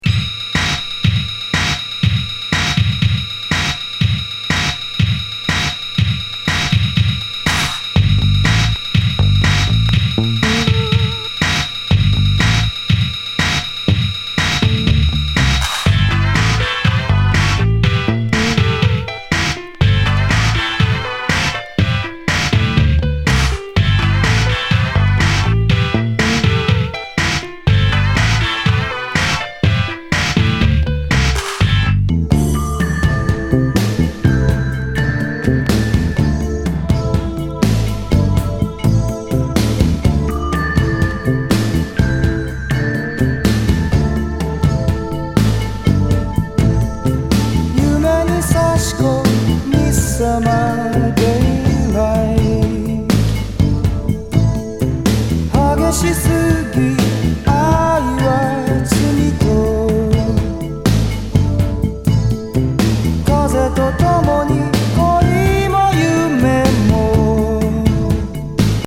エイティーズNWコールド・ファンク
アンニュイ・バレアリック・メロウ